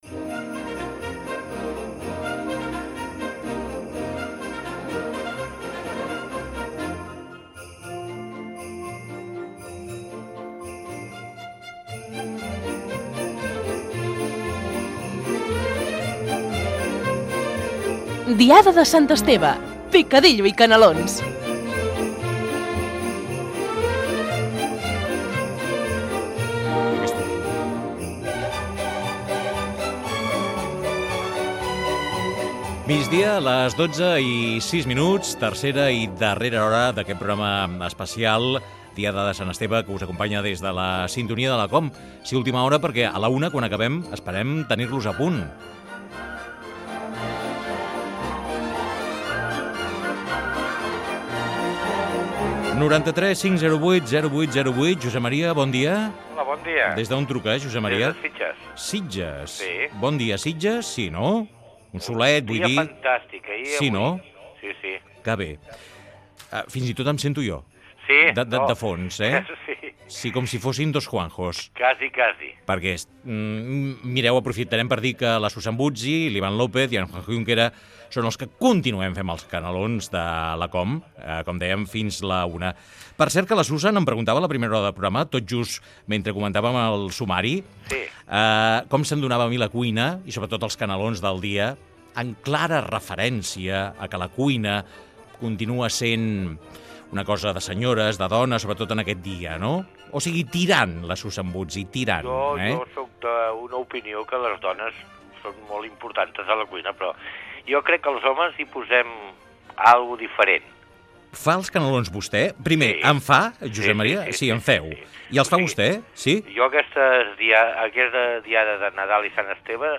Indicatiu del programa, hora, inici de la tercera hora i conversa amb un oient sobre el canelons
Entreteniment